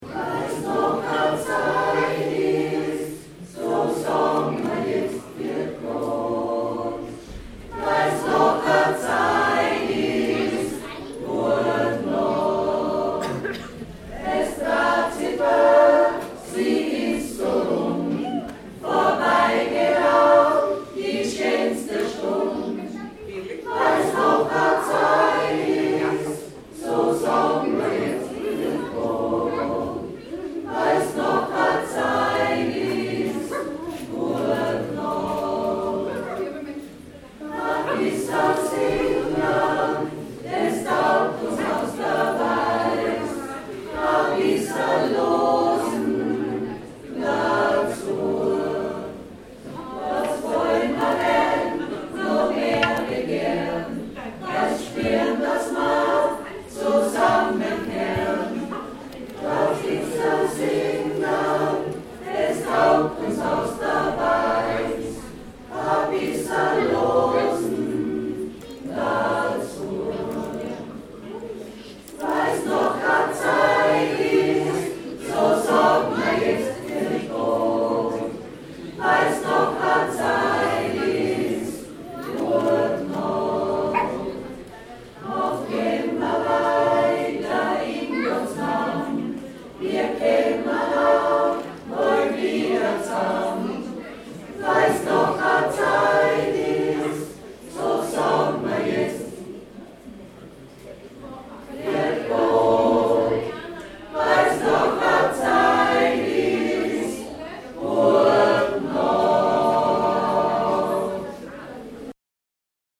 XANGSMEIEREI-Probe 03.10.2016